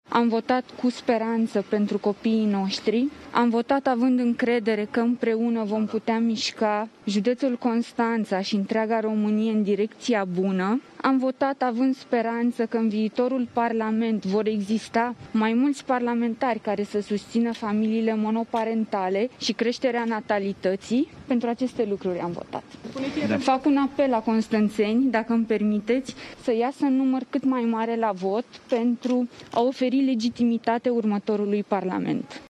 Candidat al PMP Constanţa pentru un mandat de deputat, Elena Băsescu a declarat, la ieşirea de la urne, că a votat pentru cea mai bună echipă pentru judeţ şi cel mai bun program de guvernare pentru ţară.
06dec-11-Elena-Basescu-la-Constanta.mp3